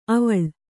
♪ avaḷ